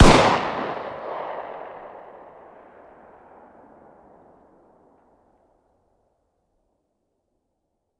fire-dist-40sw-pistol-ext-01.ogg